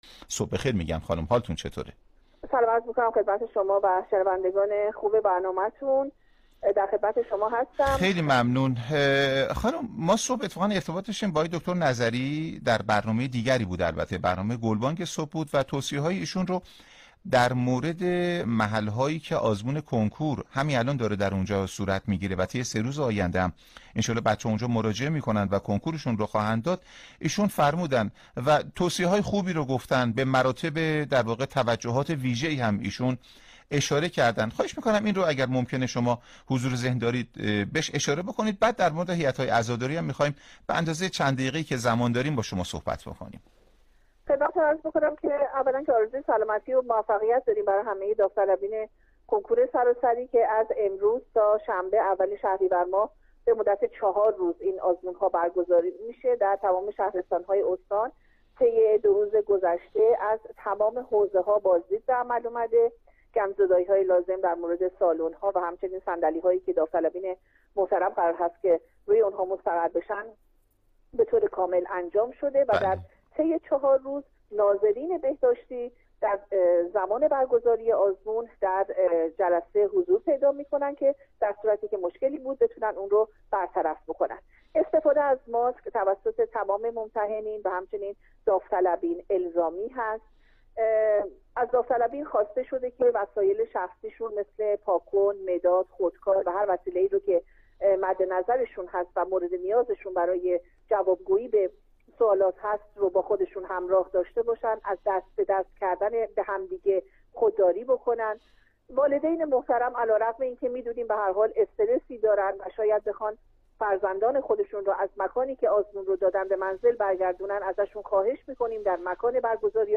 گفتگوی تلفنی برنامه رادیویی